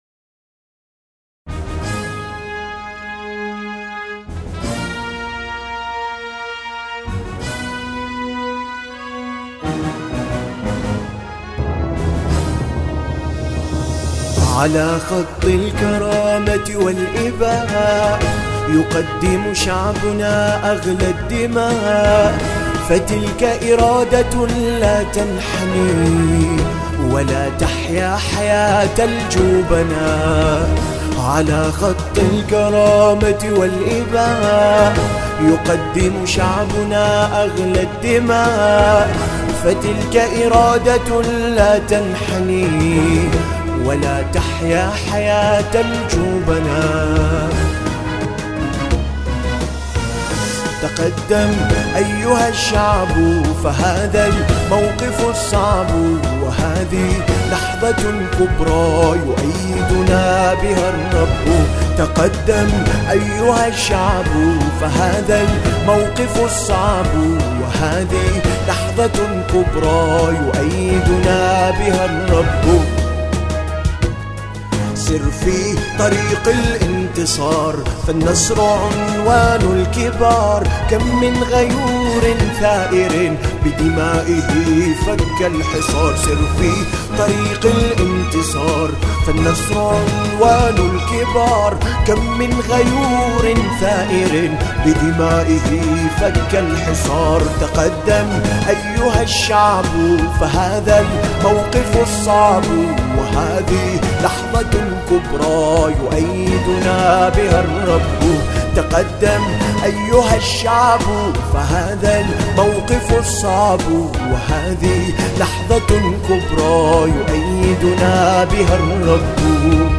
أناشيد بحرينية نشيد